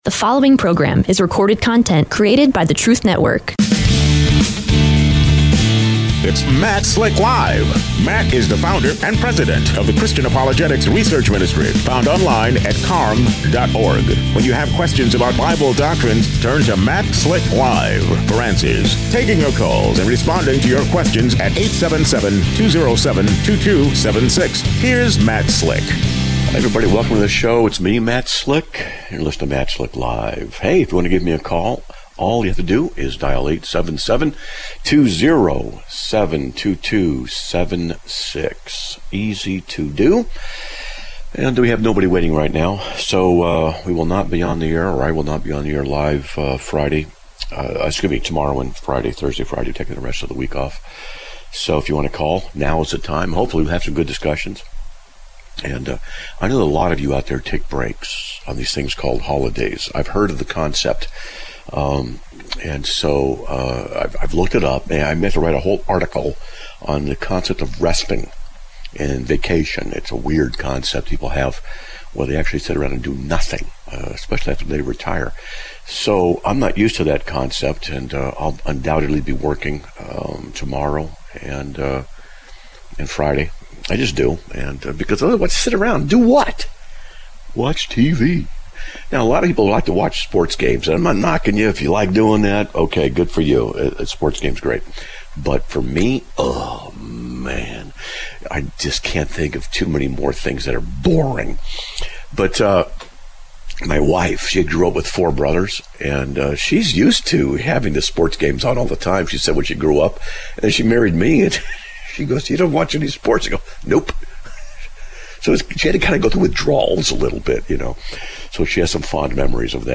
Live Broadcast